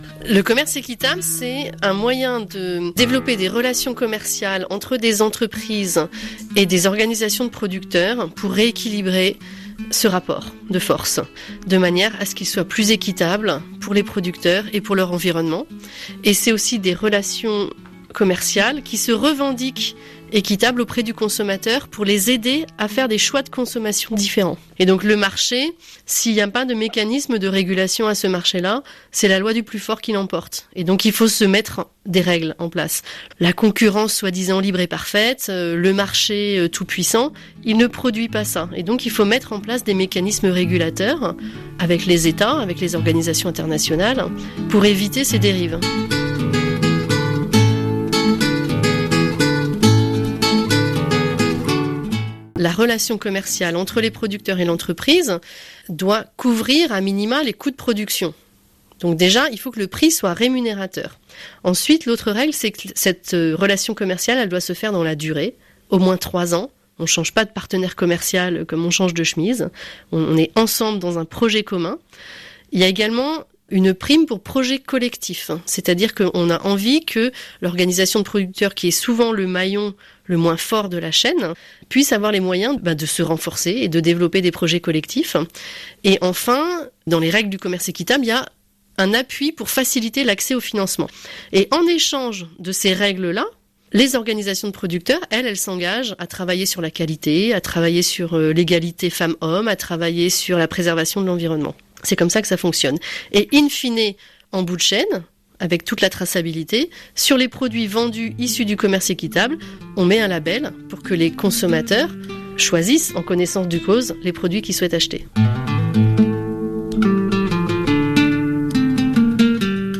Compréhension orale
[musique]